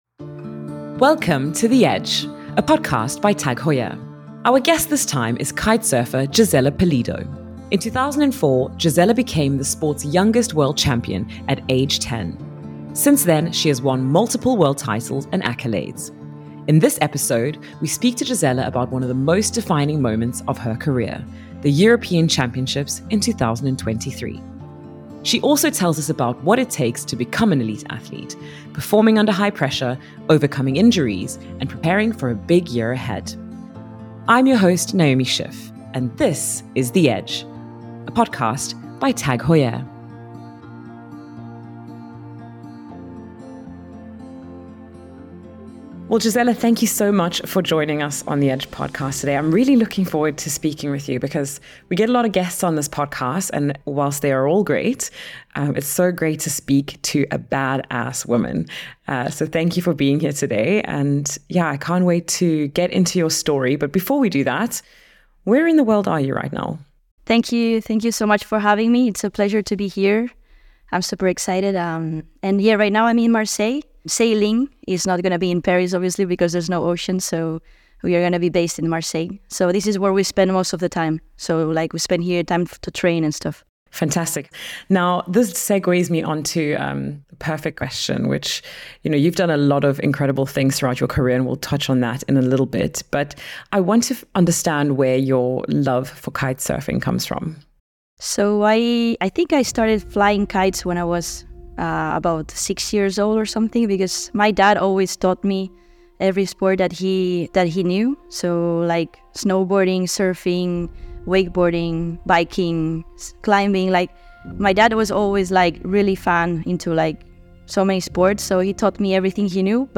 Our guest this time is kitesurfer Gisela Pulido.
In this episode, we speak to Gisela about one of the most defining moments of her career: the European Championships in 2023. She also tells us about what it takes to become an elite athlete, performing under high pressure, overcoming injuries and preparing for a big year ahead. Presented by your host Naomi Schiff, this is The Edge, a podcast by TAG Heuer.